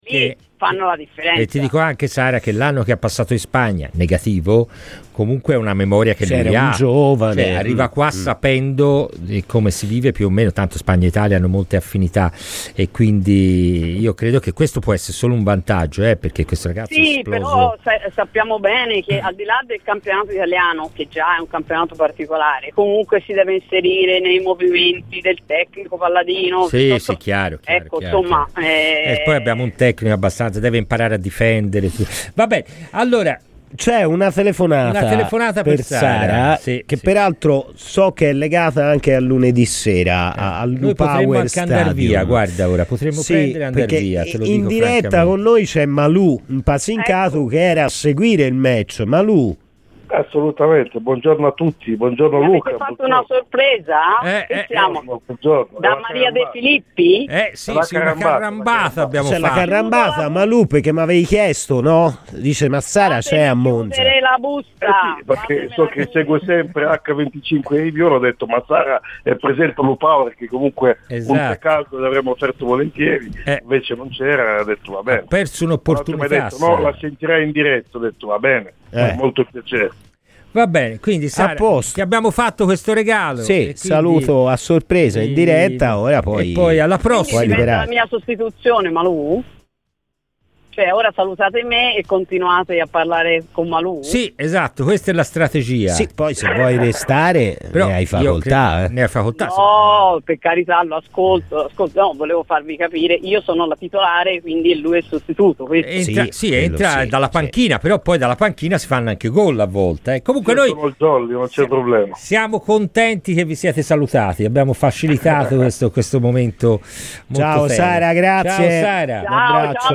Palla al centro" in onda su Radio FirenzeViola